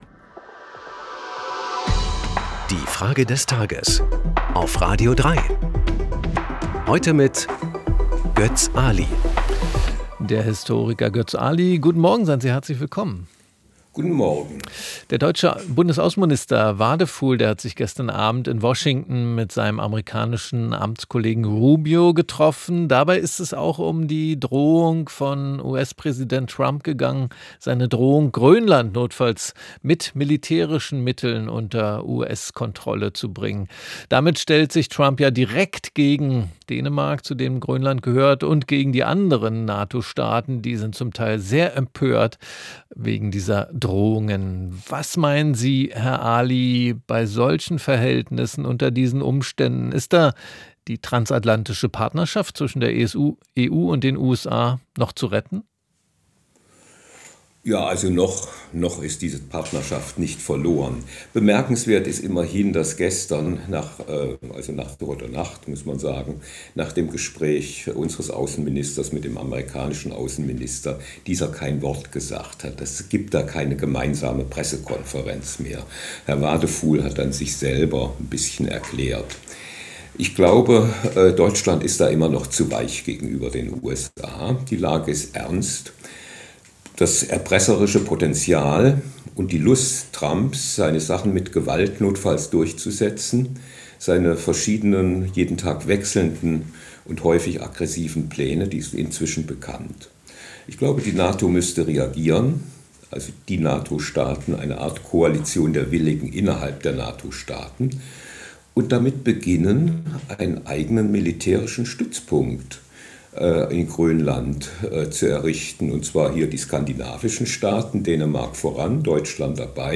Unsere Frage des Tages an den Historiker Götz Aly